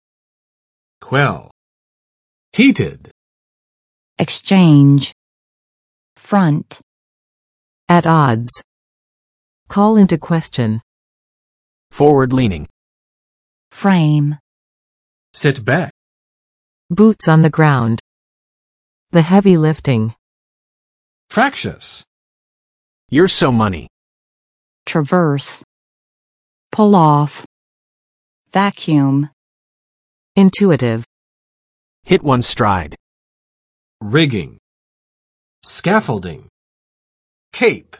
[kwɛl] v. 镇压；平息；压制；消除；减轻